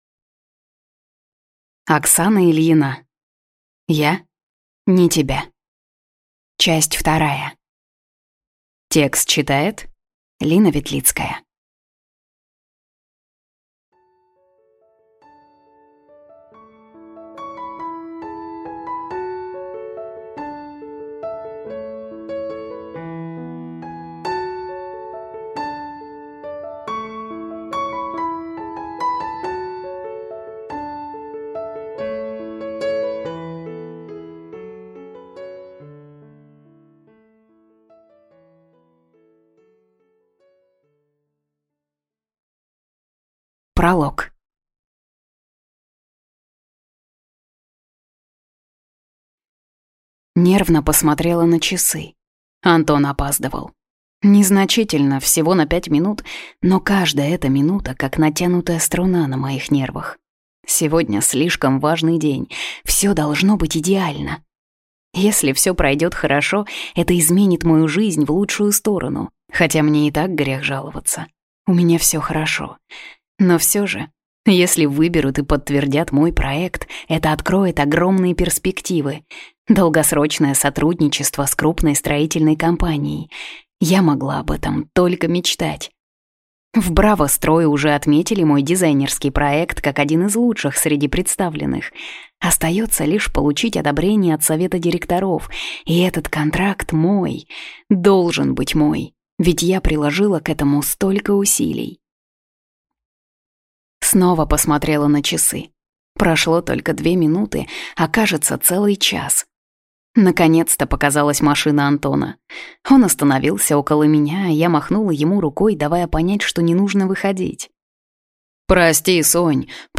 Аудиокнига Я (не) тебя. Часть 2 | Библиотека аудиокниг